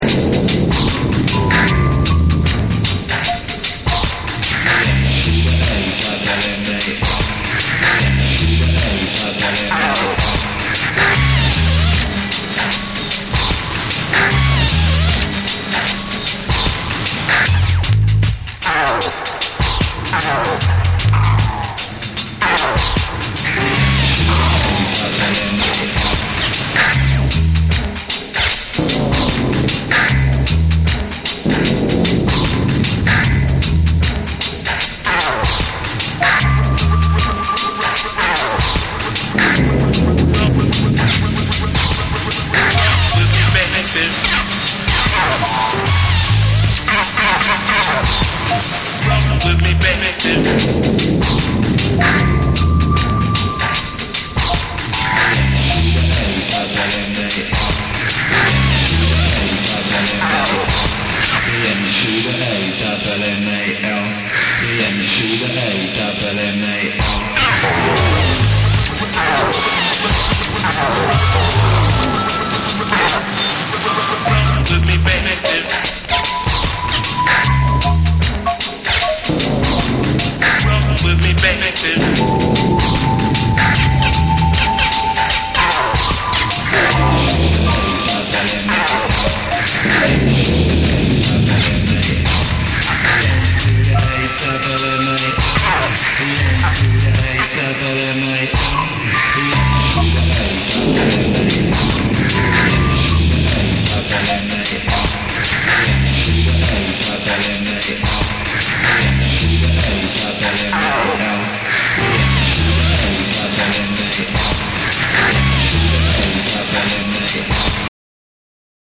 TECHNO & HOUSE / DISCO DUB